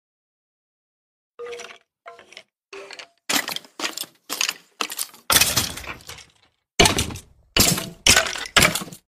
Sound Effect - Minecraft Skeleton